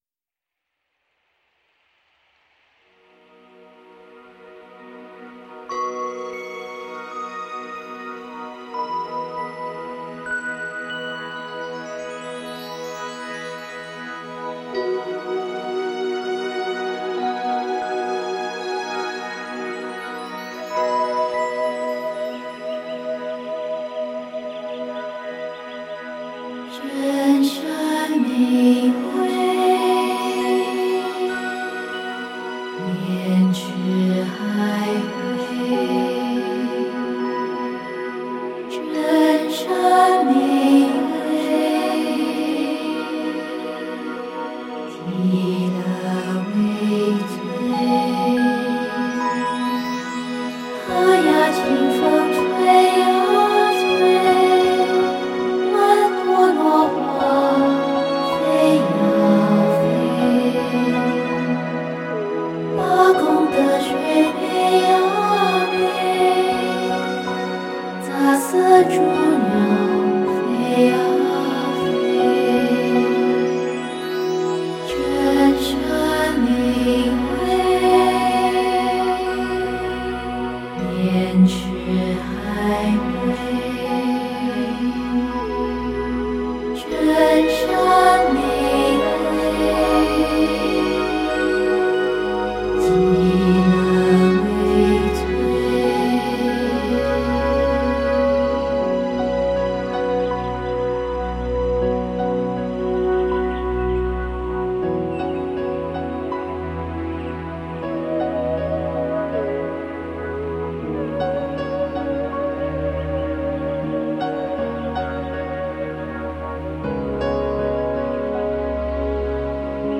全球首张歌颂极乐世界音乐专辑（DSD）
她的歌声，平静、柔和、优美，充满天女般空灵之美。她的音乐，清新、自在、抒怀，充满松放心神的新世纪之美。